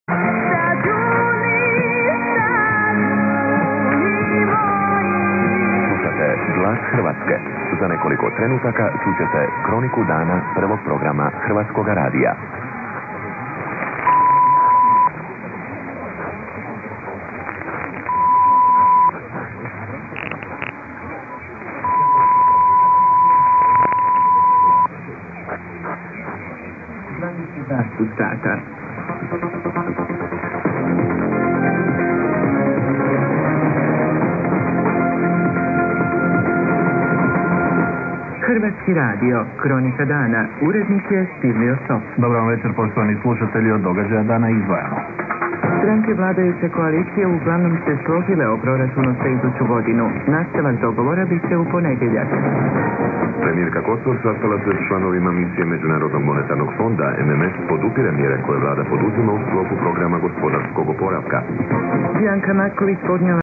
MP3 Sound Clips - International Medium-Wave DX
Featured Recordings: Powder Point - Duxbury, MA, USA - 11 NOV 2010
Receiver = Microtelecom Perseus, Antenna = 2 m by 2 m car-roof Micro-SuperLoop to W7IUV Amplifier